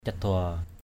/ca-d̪ʊa / (đg.) làm rẽ, lãnh canh = diviser en deux. hamu ngap cadua hm~% ZP cd%& ruộng làm rẽ, ruộng lãnh canh.